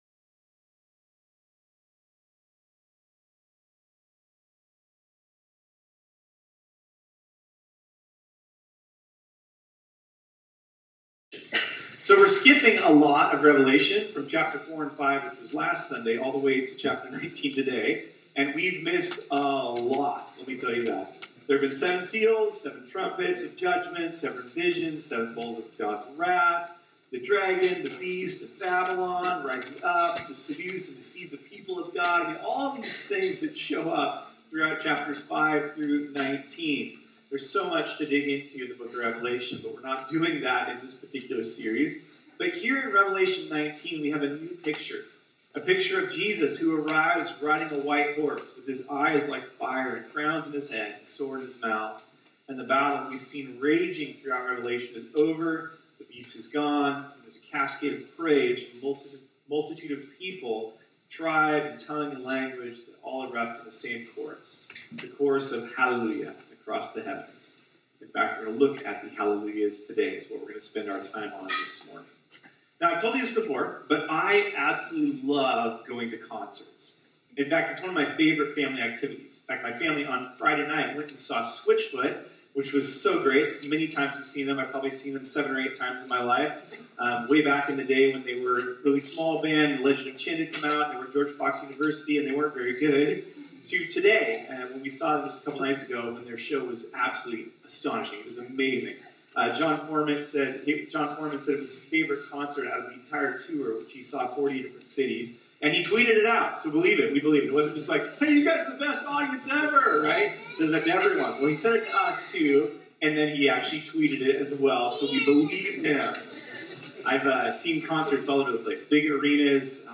This sermon was originally preached on Sunday, April 14, 2019.